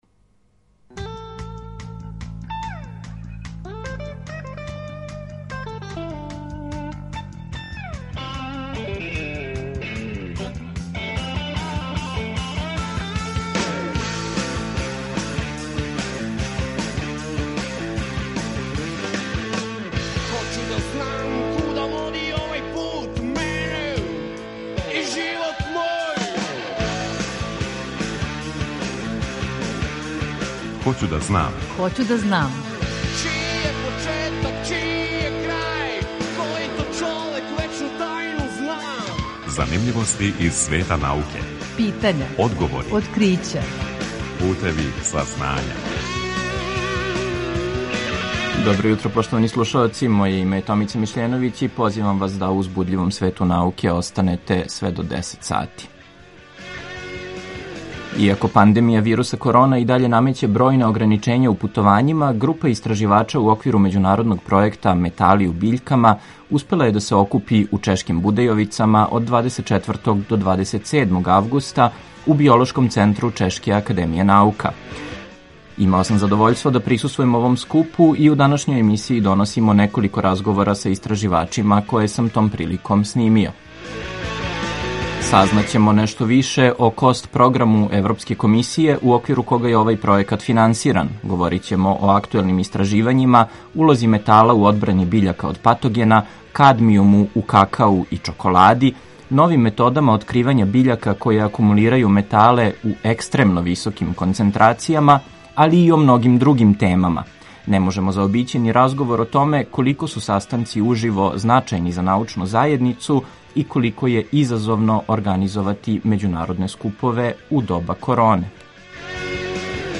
У данашњој емисији доносимо разговоре са истраживачима снимљење на овој међународној конференцији. Иако се интензивно изучавају, сложене интеракције метала и биљака и даље представљају загонетку за научнике.